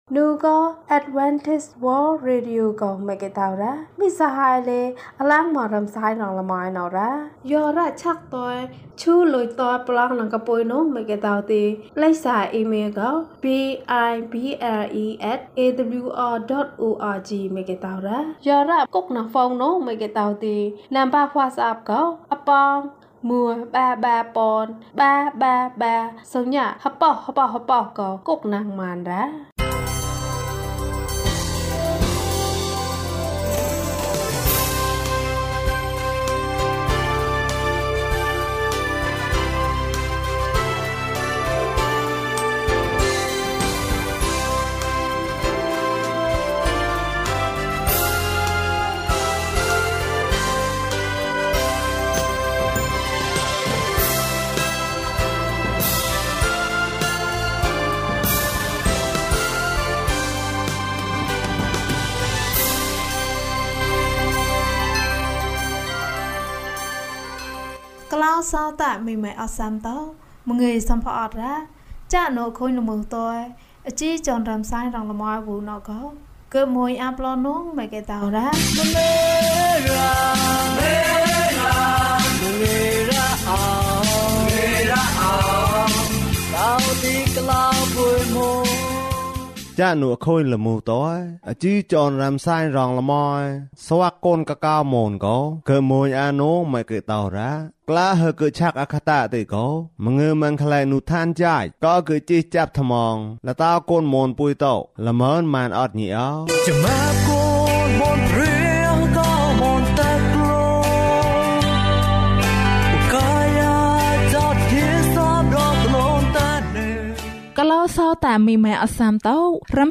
ခရစ်တော်ထံသို့ ခြေလှမ်း။၄၂ ကျန်းမာခြင်းအကြောင်းအရာ။ ပုံပြင်။ ဓမ္မသီချင်း။ တရားဒေသနာ။